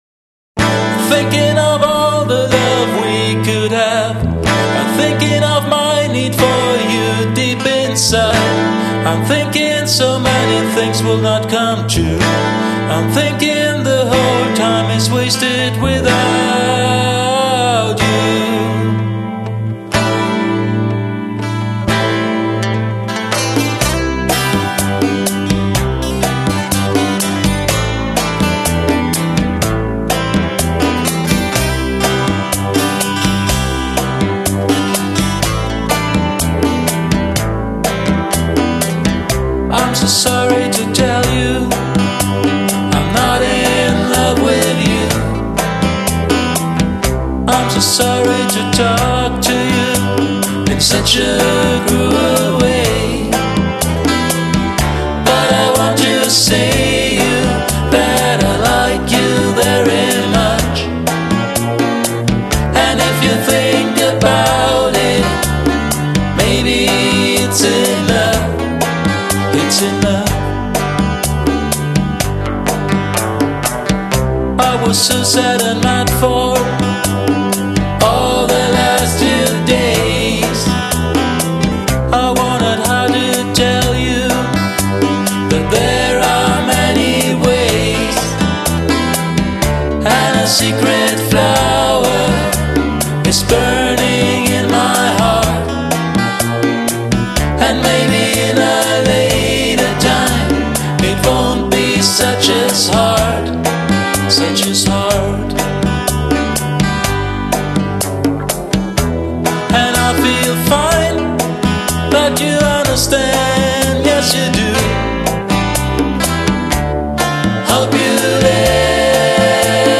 Keyboards
Gesang